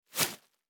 rustle.49ad3e0c.mp3